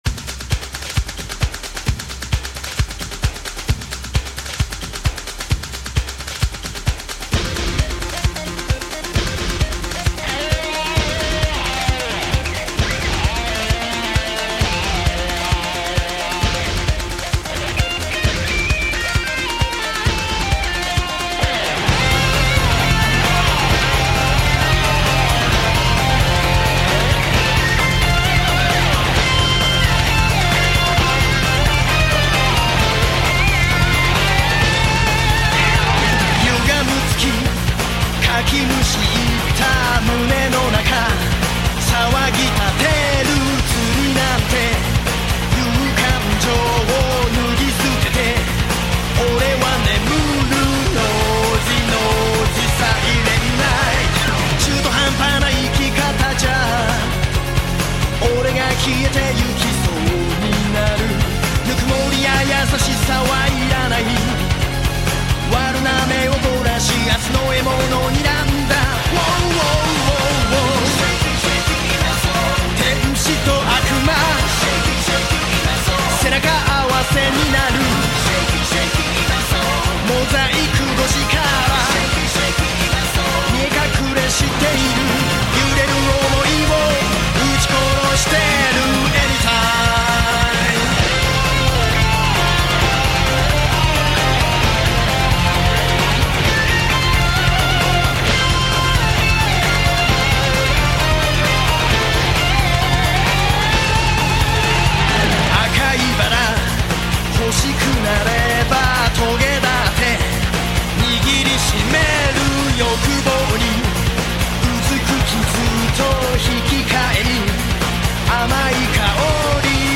песня в исполнении Такаги Васару (Тоби) из видео "Как поют сейю героев "Наруто" -